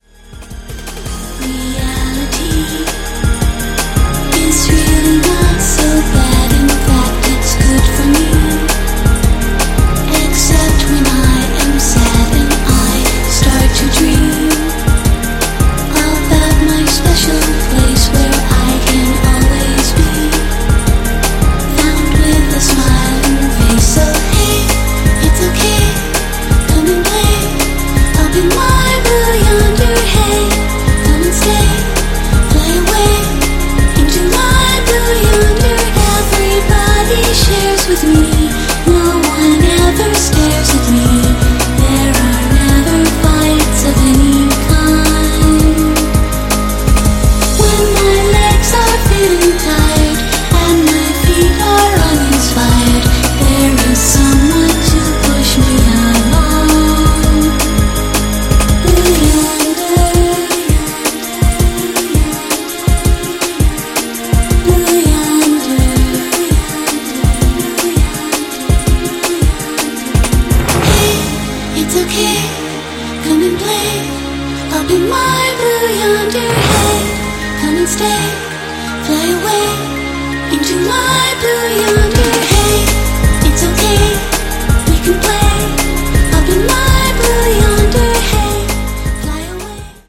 ジャジーでディープなリズミカル・トラックは流石な仕上がりです。
ジャンル(スタイル) DRUM N BASS / HOUSE